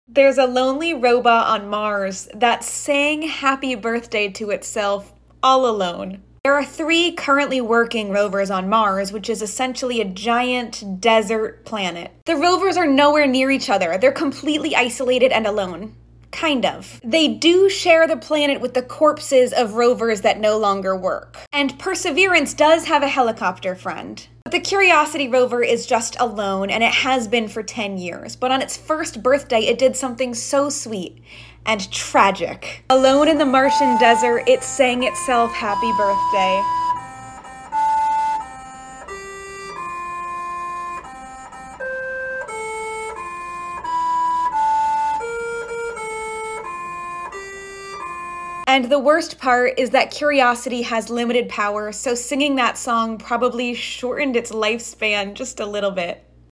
Mars rover Curiosity sang happy birthday to itself…